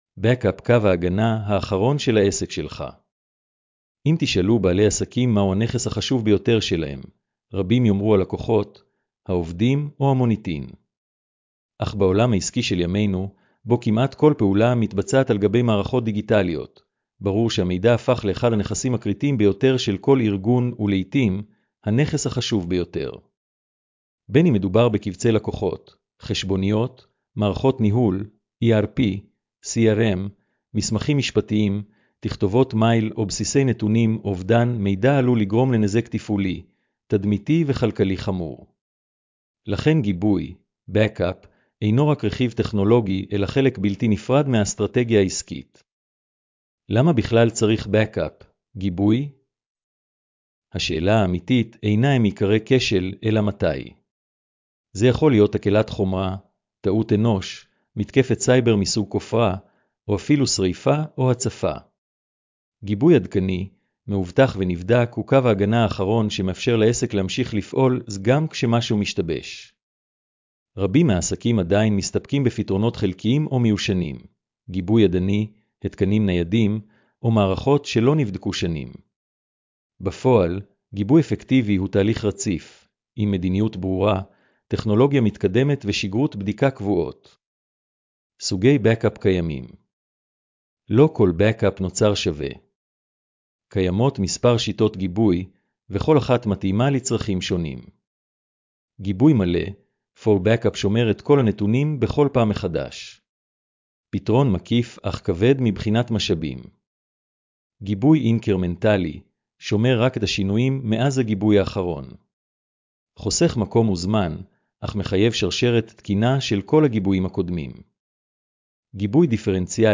השמעת המאמר לכבדי ראייה